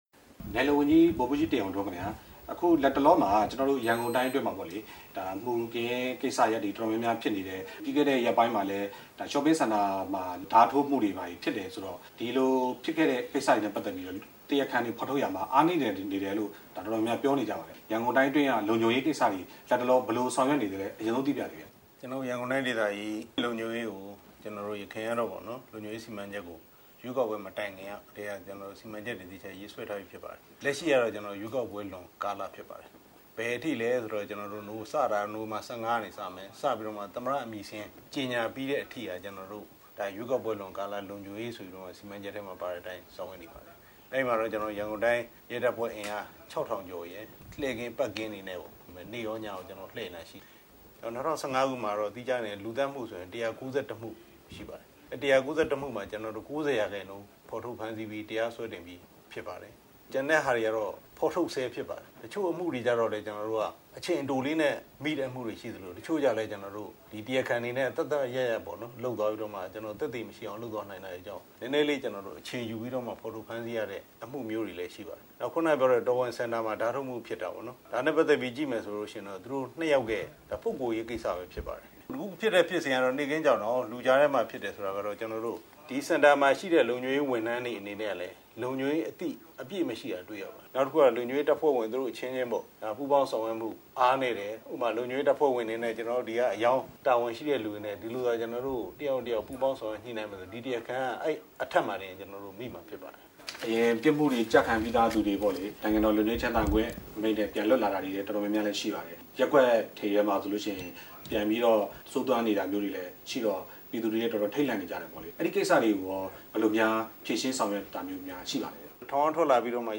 ဗိုလ်မှူးကြီး တင်အောင်ထွန်းနဲ့ မေးမြန်းချက်